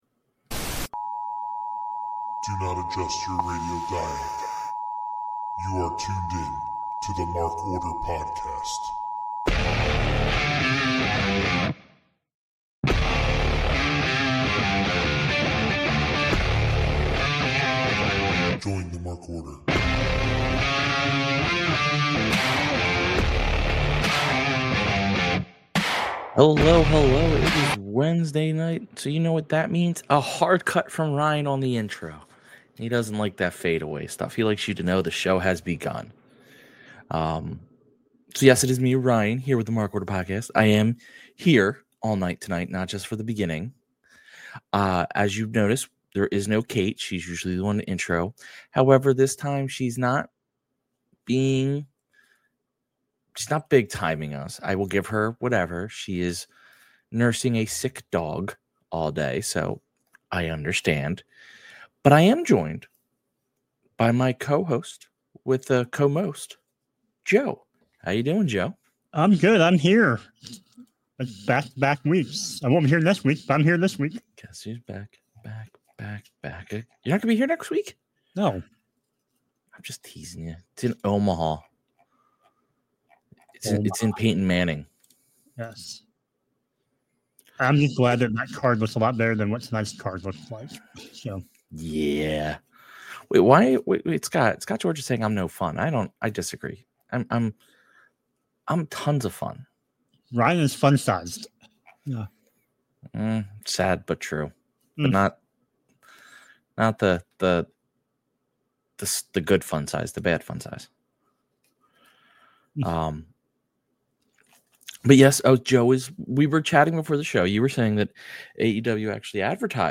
Come hang out with the two men of the Mark Order. Before reviewing Dynamite, they give their thoughts on Revolution.